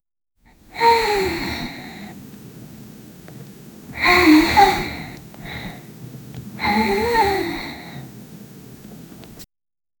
No thunder, wind. 0:10 Anime girl sleeping in bed sound 0:10 steady rain with occasional distant thunder.
anime-girl-sleeping-in-be-oe5tulmr.wav